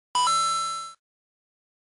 Coin